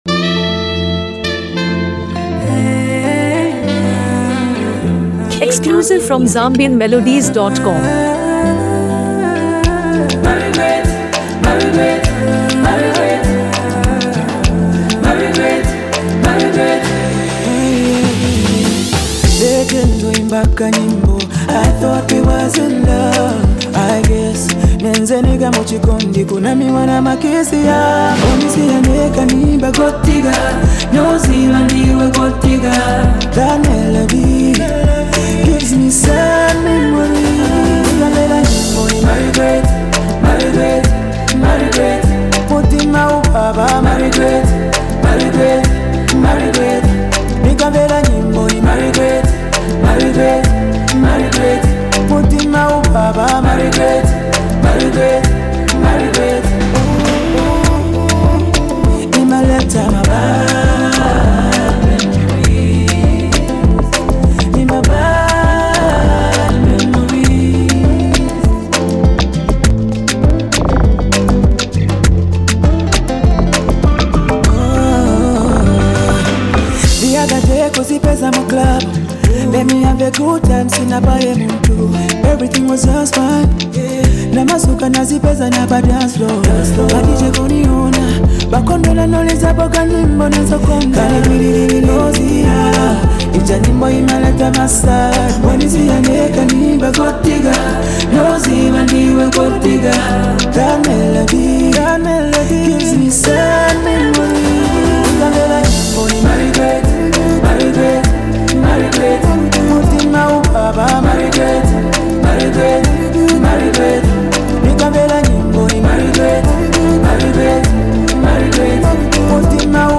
soulful melodies
delivers his verses with raw emotion and lyrical precision